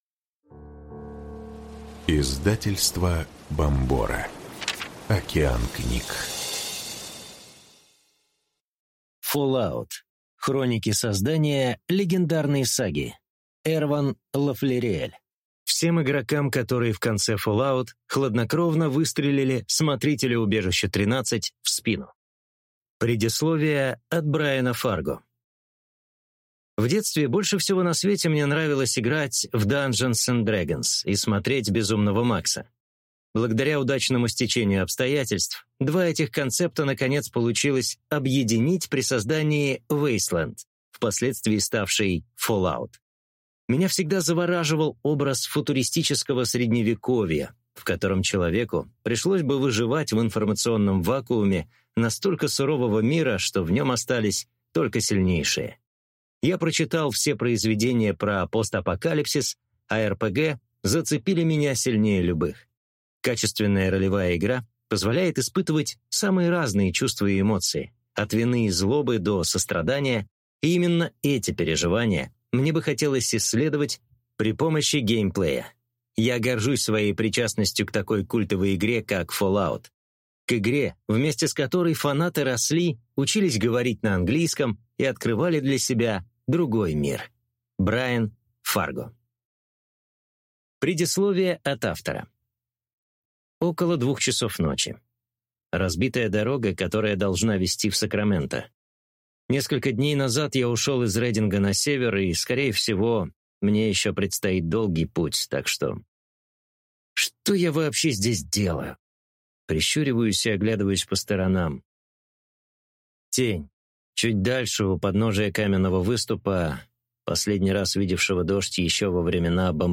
Аудиокнига Fallout. Хроники создания легендарной саги | Библиотека аудиокниг